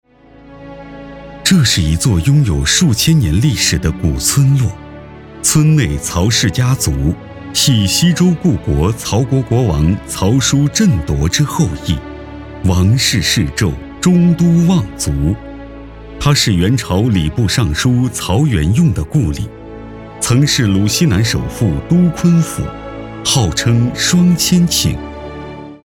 纪录片-男54-沧桑-历史纪录片.mp3